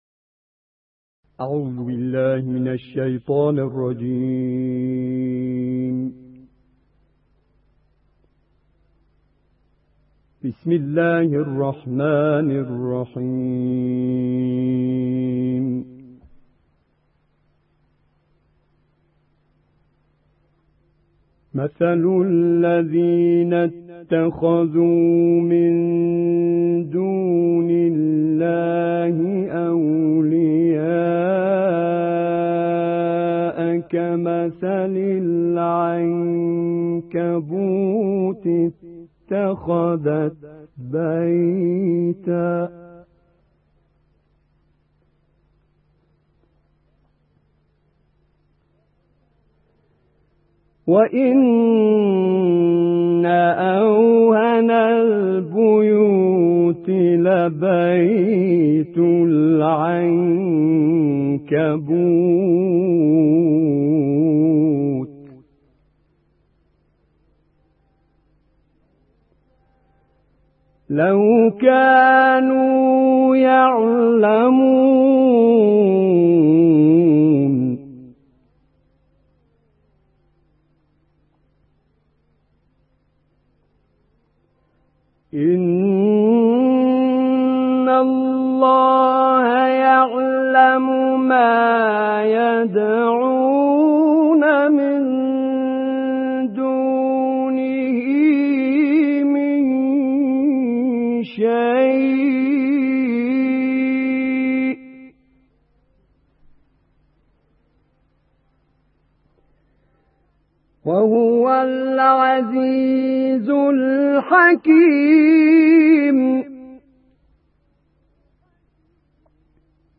تلاوت کوتاه مجلسی